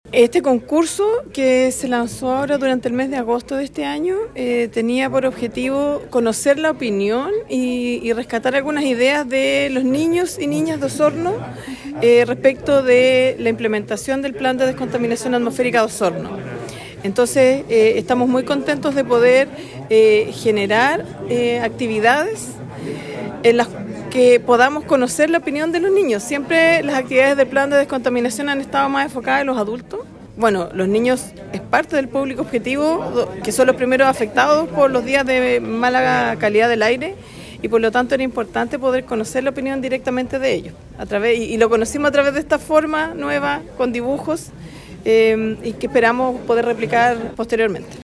La Seremi de Medioambiente de Los Lagos, Carola Iturriaga destacó esta actividad pues de esta forma se puede conocer su opinión acerca de todos los procesos de descontaminación.